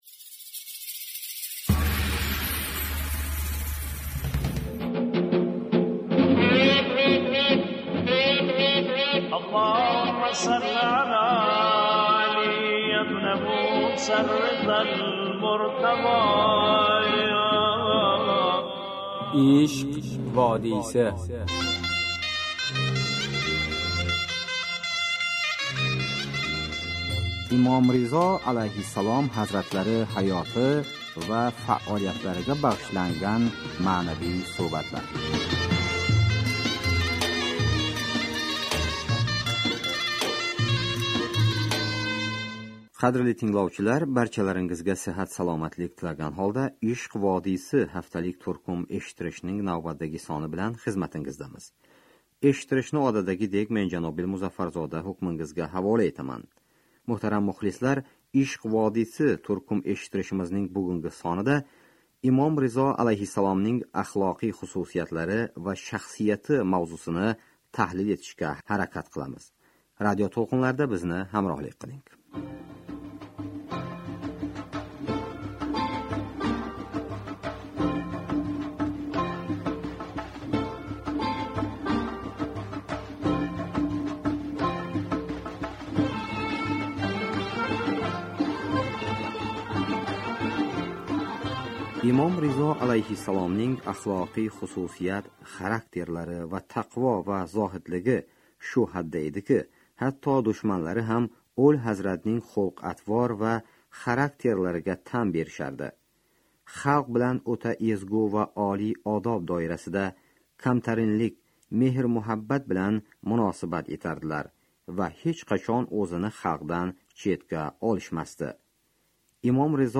Бу адабий-бадиий дастур Пайғамбаримиз (с.а.в.) невараларидан бири ҳазрат Имом Ризо (а) баракали ҳаёти ва фаолиятларига бағшланади.